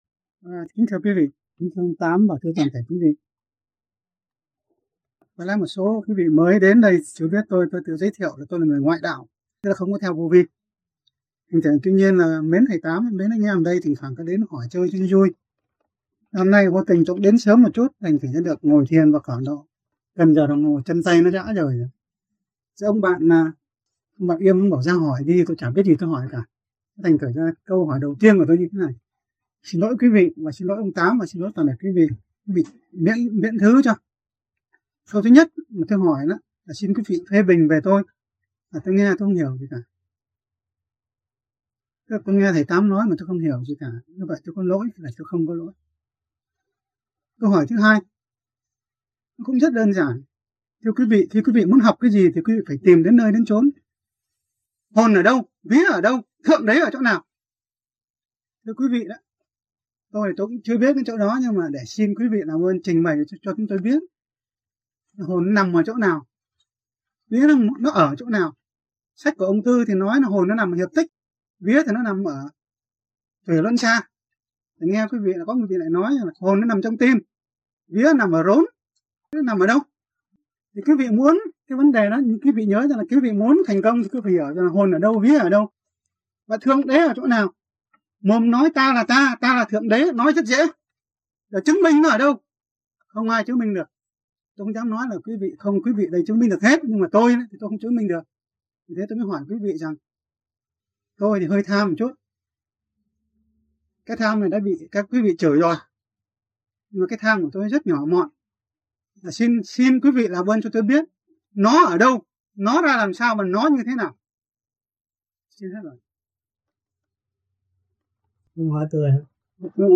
VẤN ĐẠO
THUYẾT GIẢNG